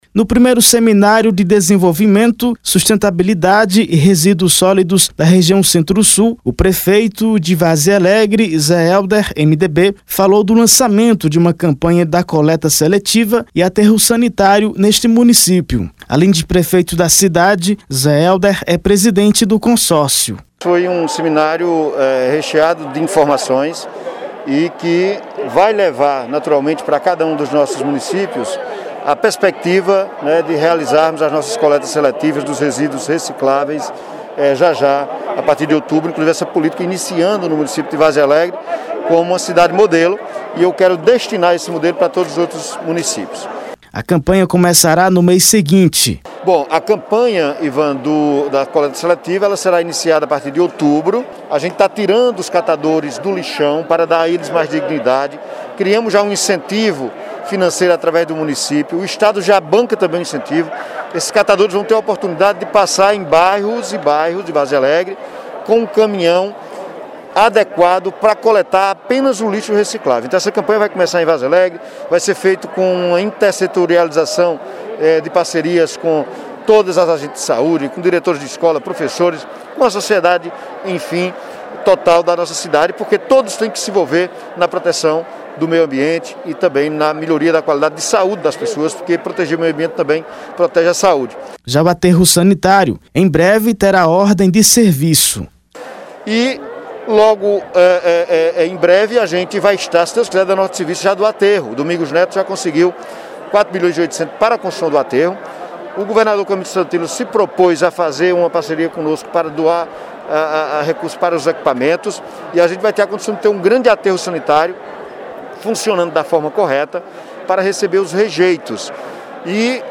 No I Seminário de desenvolvimento, sustentabilidade e resíduos sólidos da região Centro-Sul, o prefeito de Várzea Alegre Zé Helder, MDB, falou do lançamento de uma campanha da coleta seletiva e aterro Sanitário neste município.